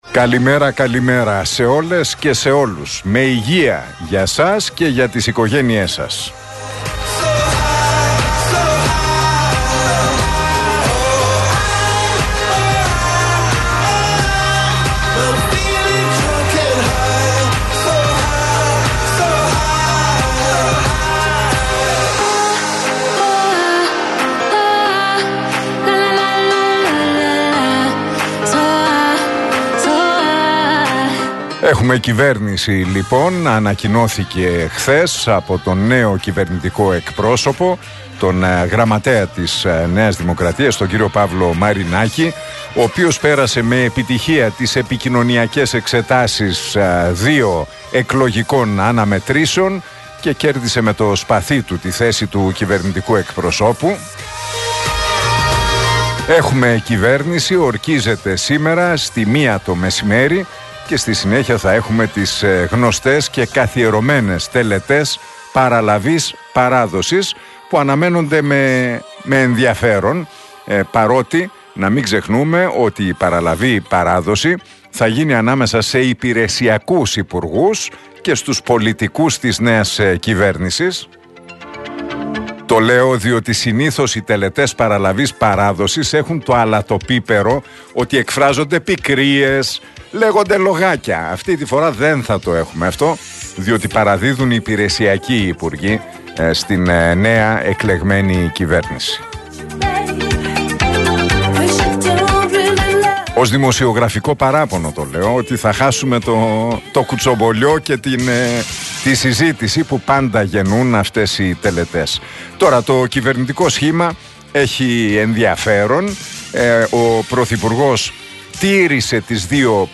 Ακούστε το σχόλιο του Νίκου Χατζηνικολάου στον RealFm 97,8, την Τρίτη 27 Ιουνίου 2023.